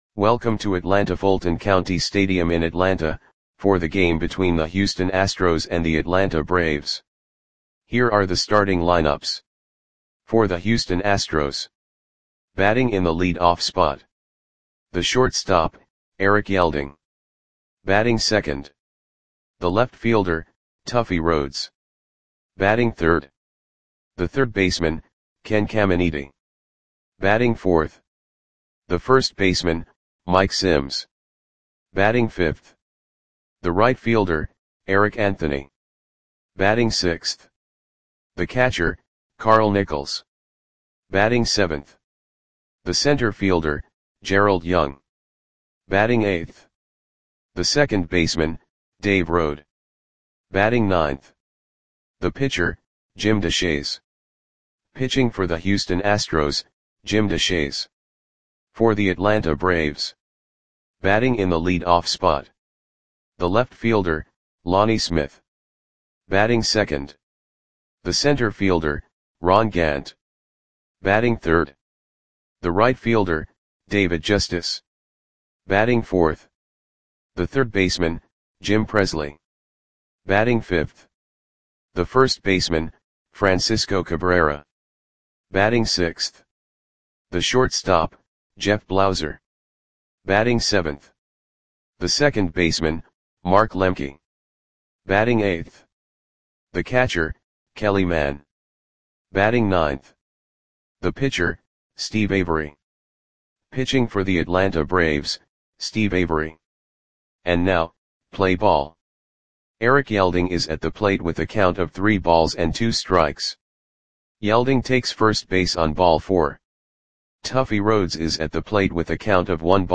Audio Play-by-Play for Atlanta Braves on September 21, 1990
Click the button below to listen to the audio play-by-play.